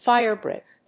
音節fíre・brìck 発音記号・読み方
/ˈfaɪɝˌbrɪk(米国英語), ˈfaɪɜ:ˌbrɪk(英国英語)/